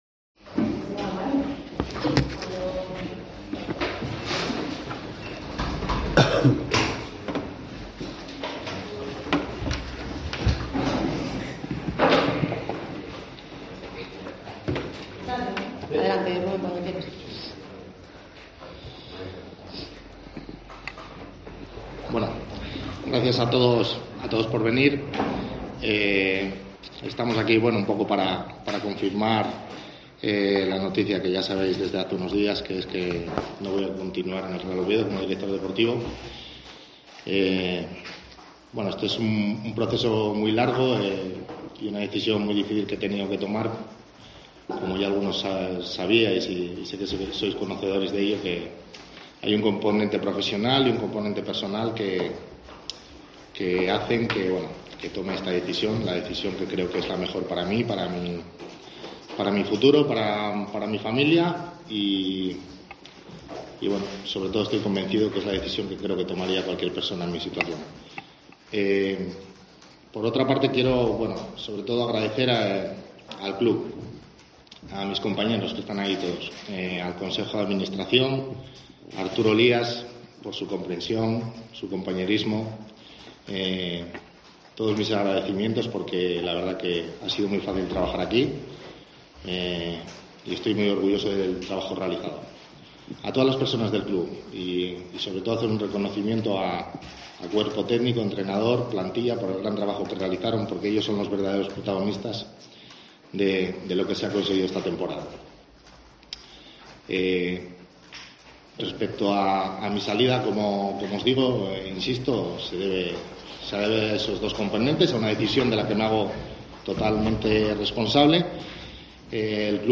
hizo irrupción en la sala de prensa del Carlos Tartiere